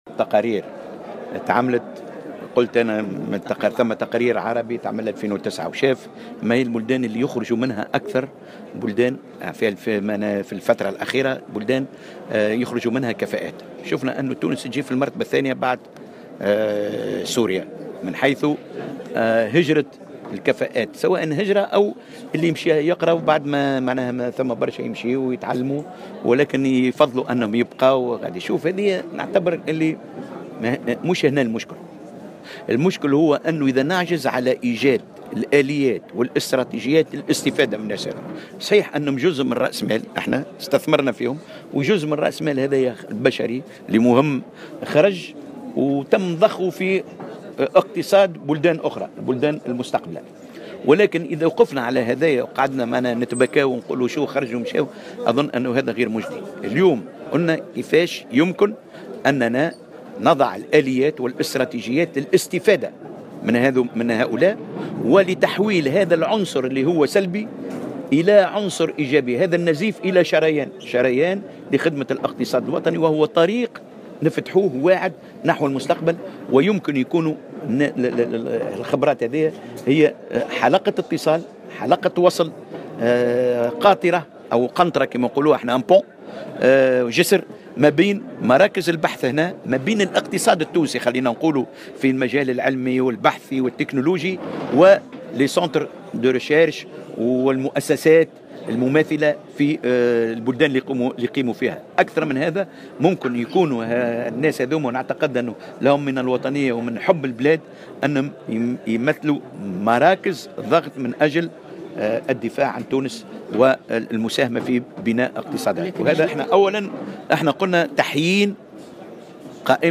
واشار الطرابلسي، في تصريح لمراسل الجوهرة أف أم، على هامش ندوة للكفاءات التونسية بالخارج، أشرف عليها صباح اليوم الأربعاء رئيس الحكومة يوسف الشاهد، إلى تقرير عربي نشر سنة 2009 أثبت أن تونس تعتبر ثاني أكبر مصدر عربي للكفاءات بعد سوريا، داعيا إلى مد جسور التواصل مع هذه الكفاءات قصد استفادة الاقتصاد التونسي من إمكانياتهم.